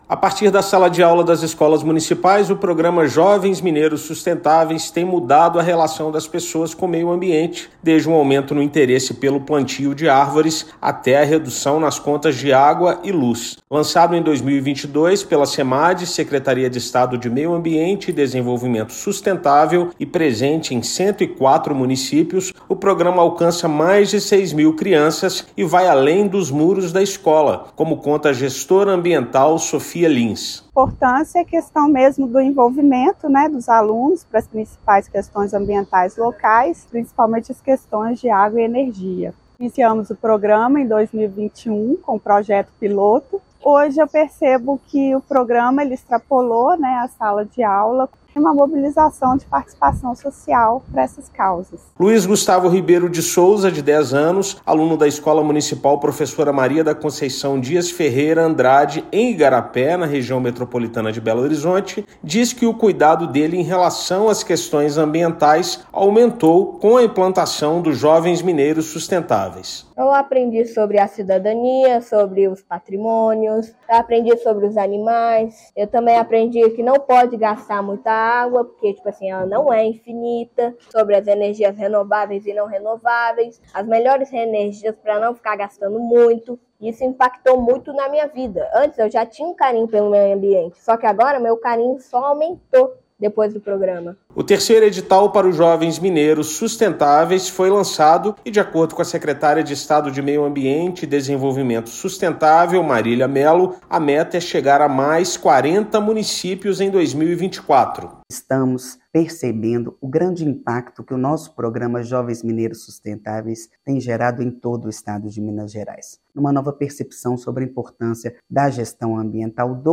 Com a iniciativa do Governo de Minas, houve uma mudança na rotina das cidades com impactos ambientais positivos, como maior procura por plantios de árvores e reduções nas contas de luz e de água. Ouça matéria de rádio.